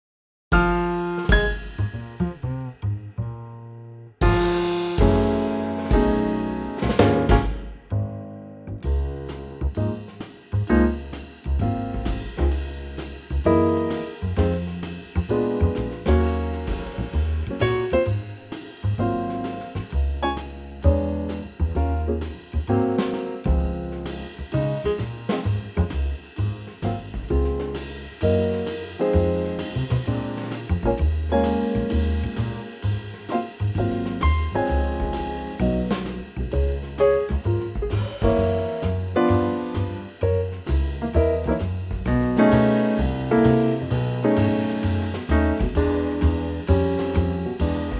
key - Db - vocal range - Ab to B